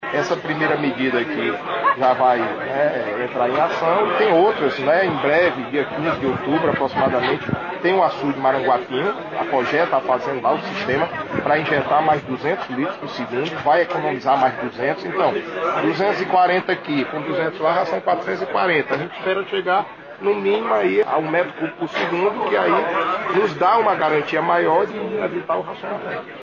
Local: ETA Gavião
Entrevistas:
Francisco Teixeira, Secretário dos Recursos Hídricos